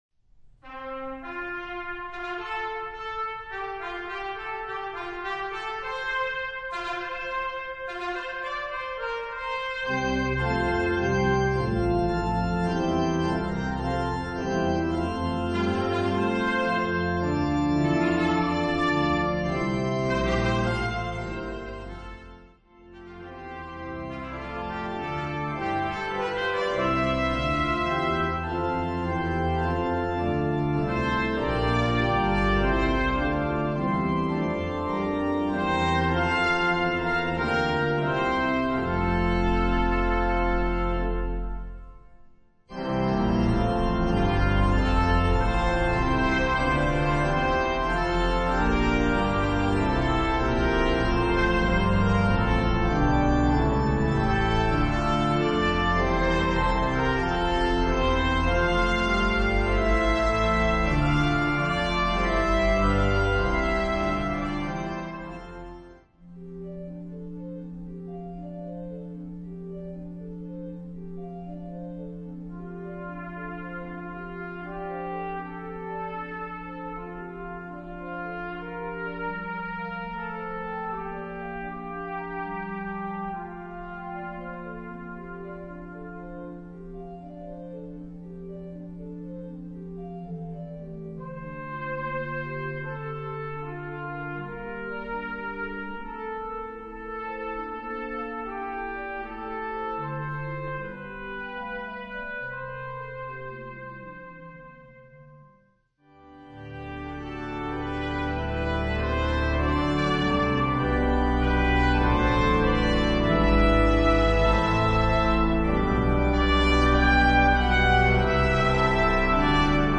Voicing: SATB, Congregation, Optional Children's Choir